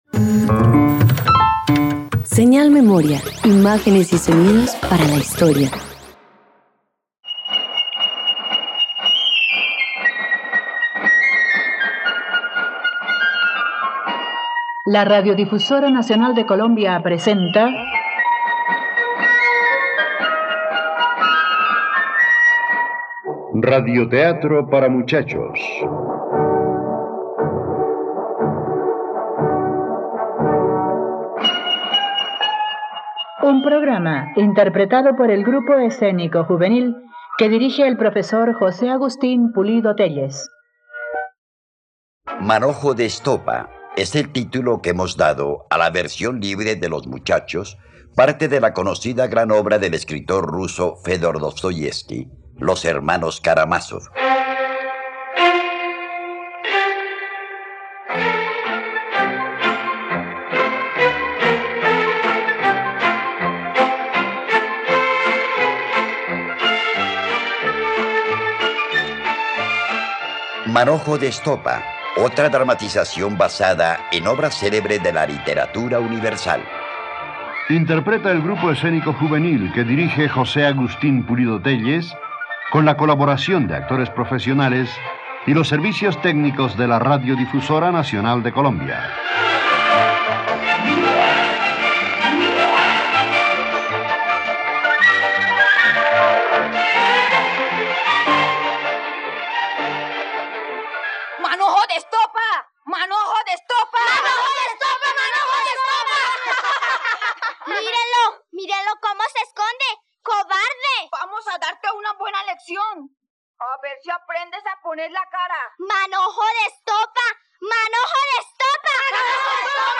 Manojo de estopa - Radioteatro dominical | RTVCPlay
..Radioteatros. Escucha la adaptación de la obra "Los hermanos Karamazov" del novelista ruso Fiódor Dostoyevsky, disponible en RTVCPlay.